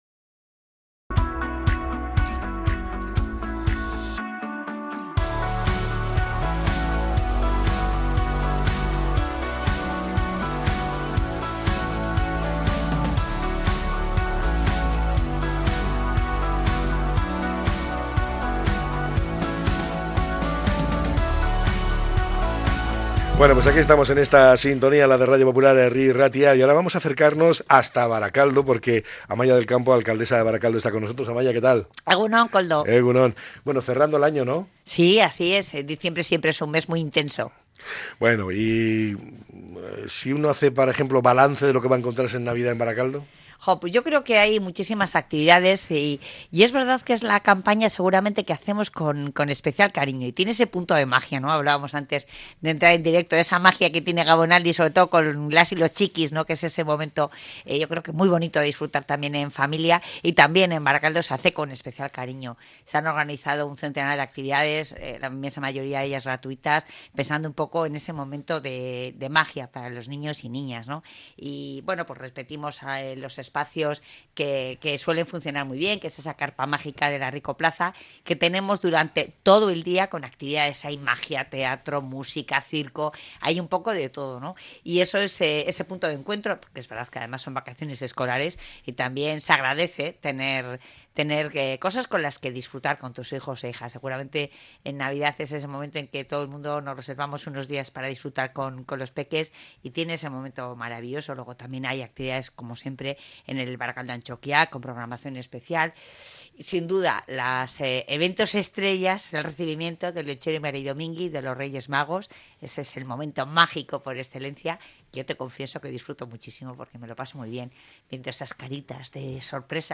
La alcaldesa de Barakaldo, Amaia del Campo, detalla esta campaña de Gabonaldia que han preparado "con especial cariño"